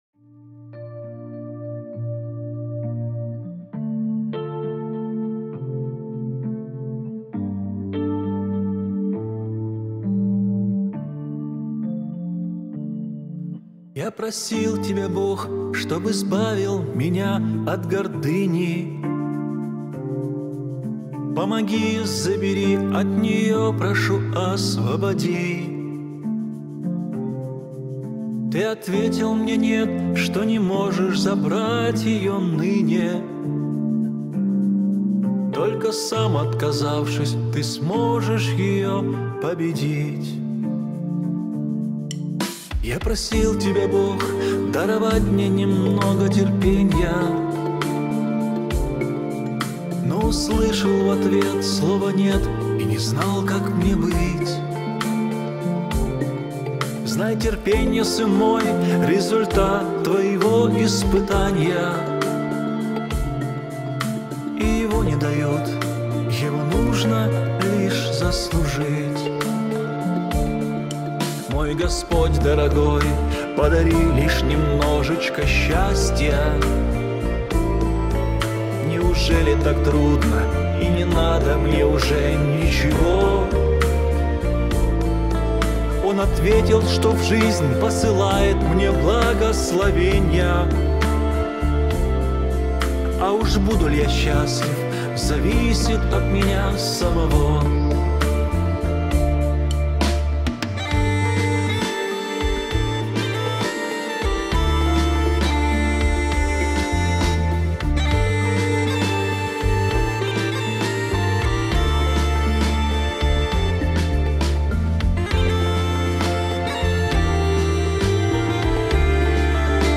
песня
131 просмотр 101 прослушиваний 5 скачиваний BPM: 75